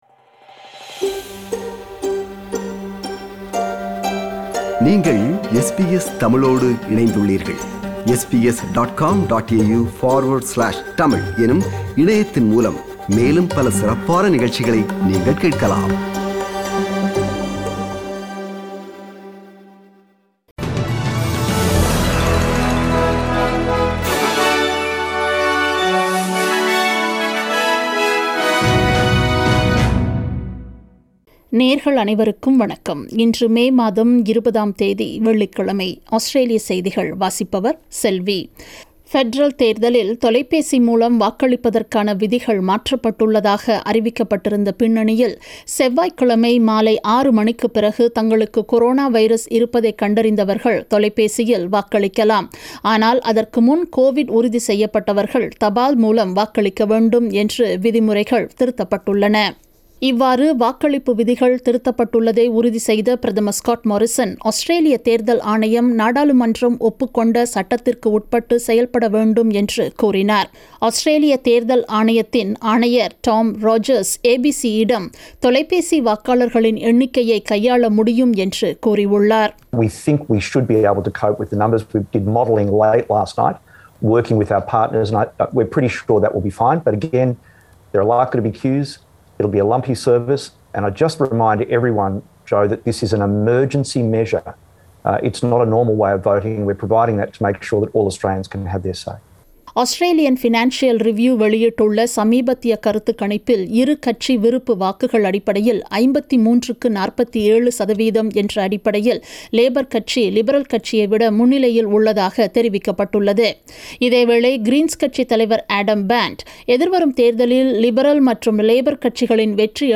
Australian news bulletin for Friday 20 May 2022.